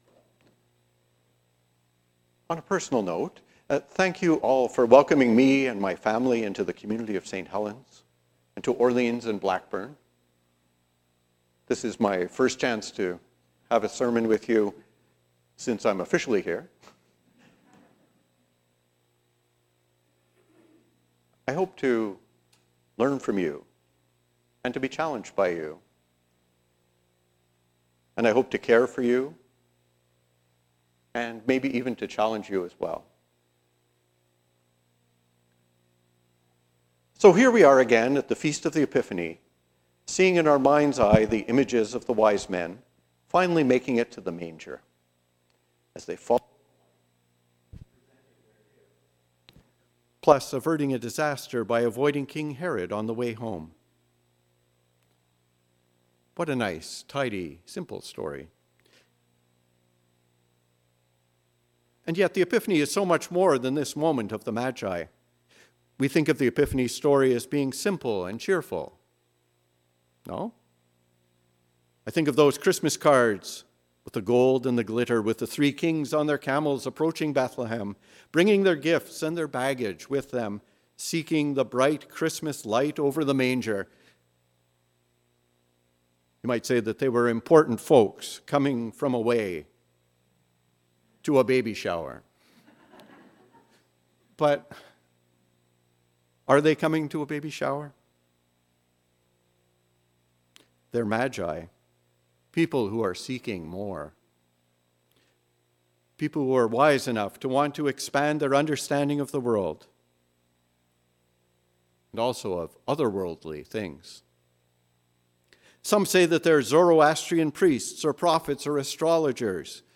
The story of our voyage towards the light: a sermon for the Feast of the Epiphany
Sermon-8-Jan-2023.mp3